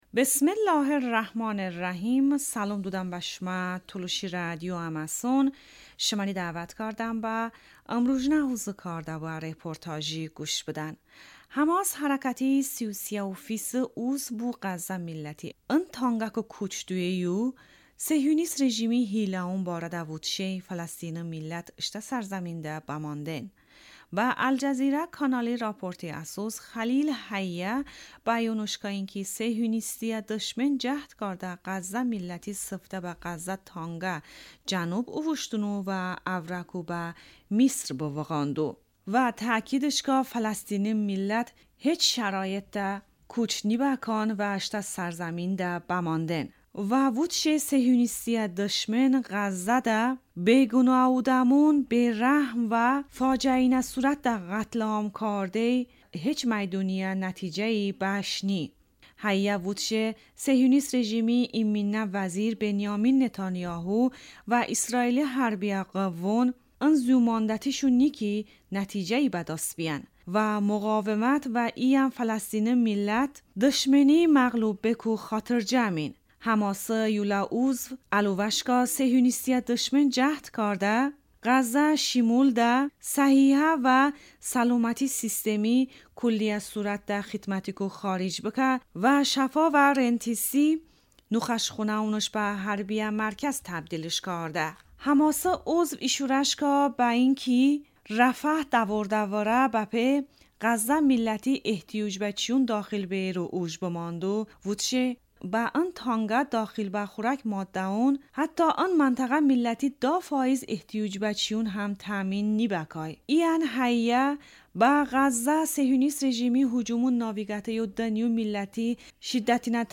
Tolışi rədiyo ım rujnə reportaj